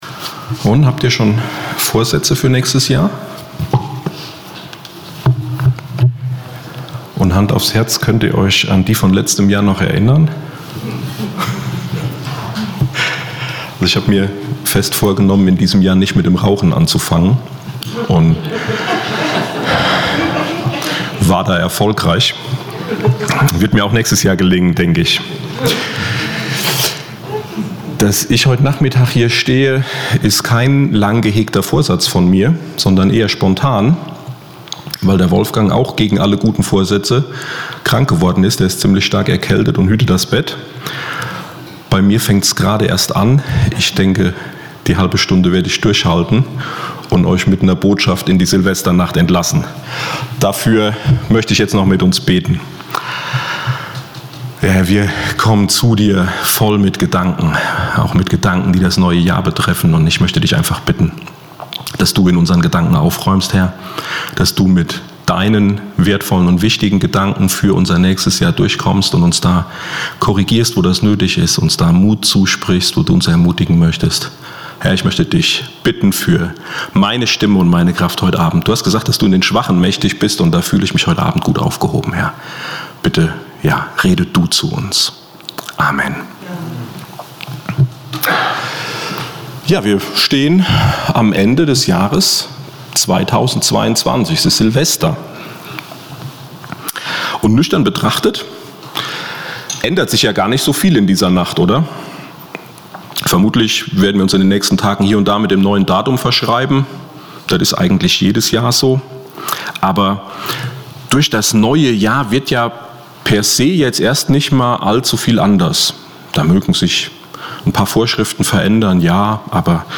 Jahresabschlussgottesdienst 2022